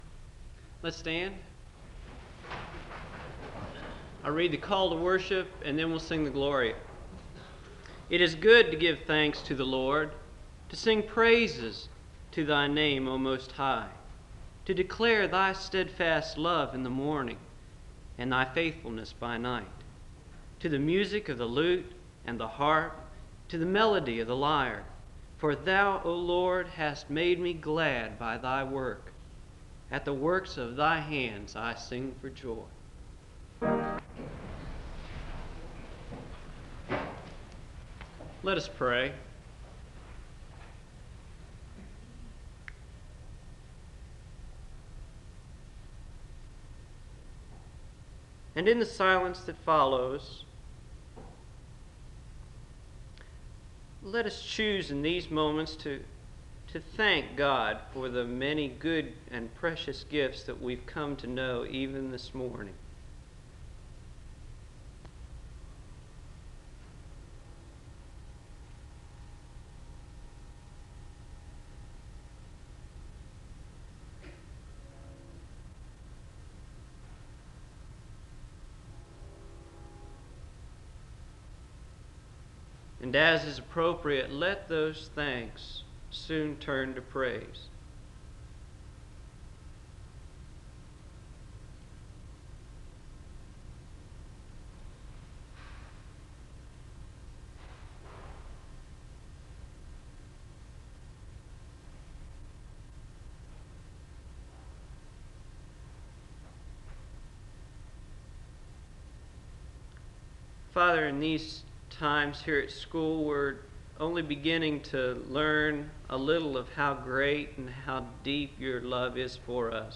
The service begins with a benediction and a word of prayer (00:00-04:19). The speaker reads from Isaiah 6 (04:20-07:40).